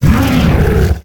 Leozar_Cry.ogg